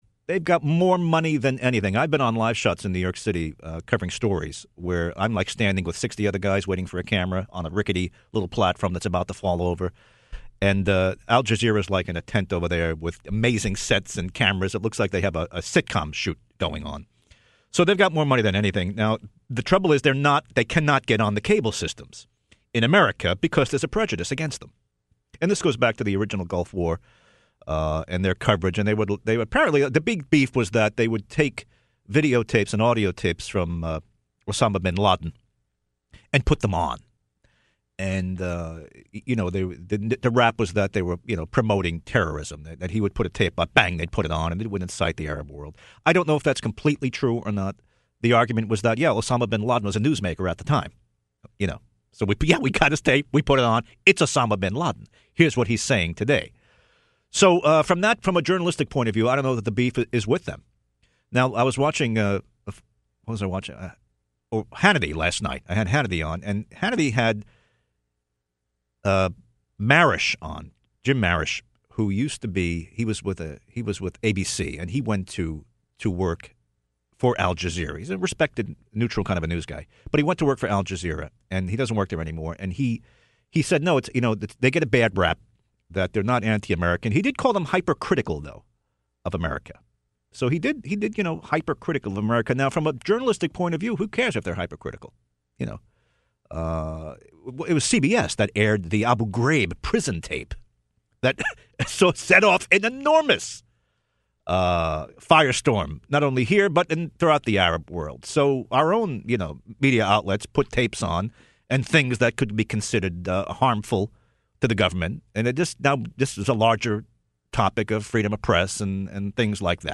Saturday morning radio show on WPRO in Providence